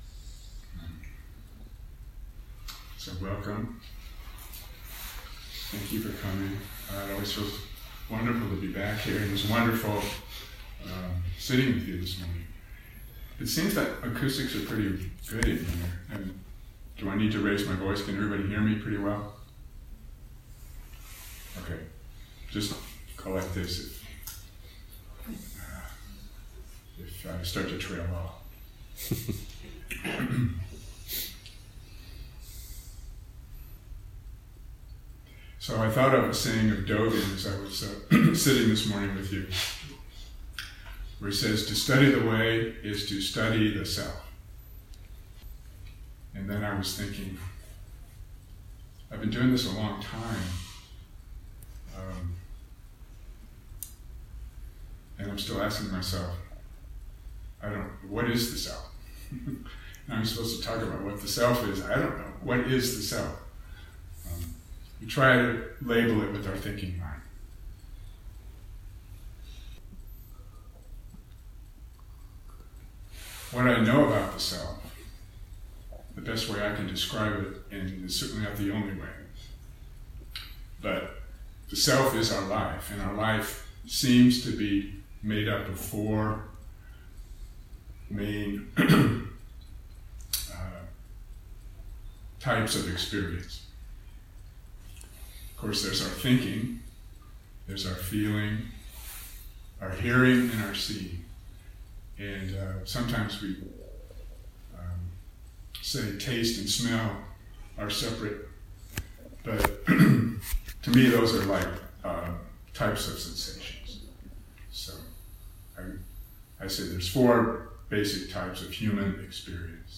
Dharma Talk